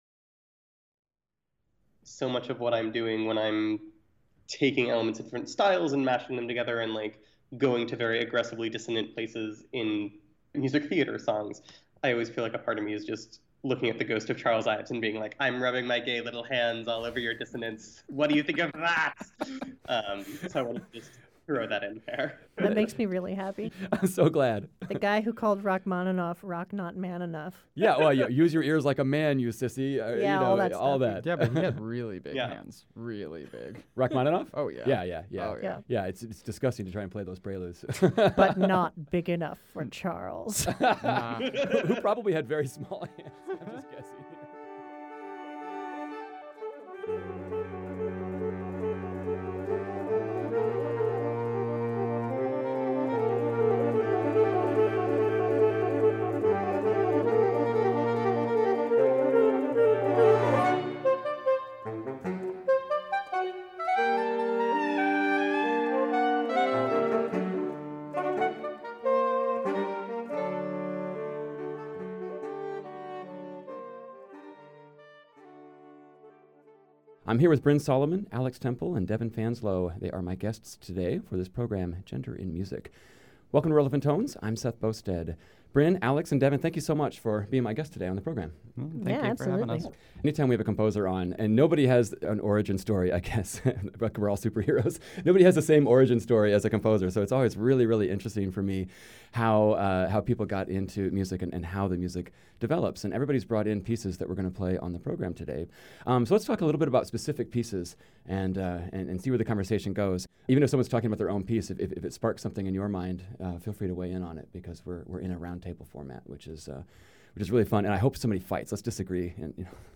One of the most important new developments in contemporary classical music is part of a wider societal trend of gender fluidity. We talk with three composers who have transitioned about their music…